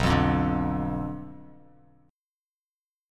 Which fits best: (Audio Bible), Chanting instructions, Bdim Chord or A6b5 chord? Bdim Chord